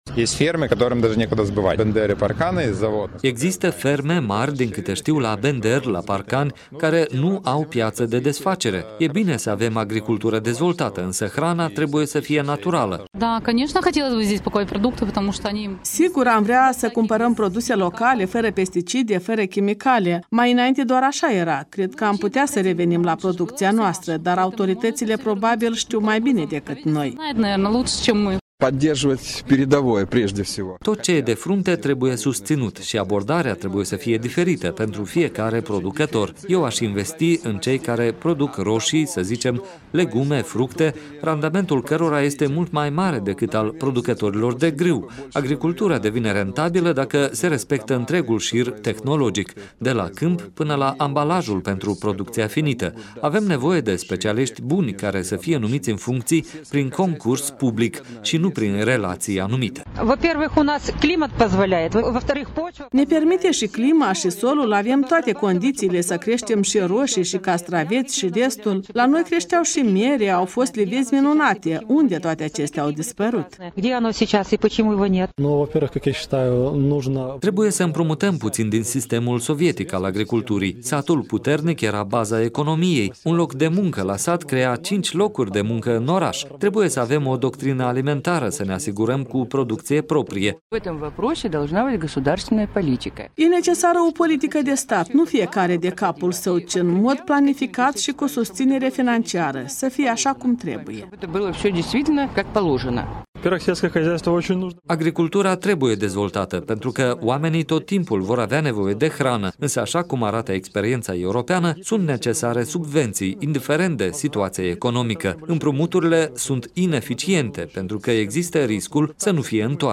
Vox Populi la Tiraspol şi Bender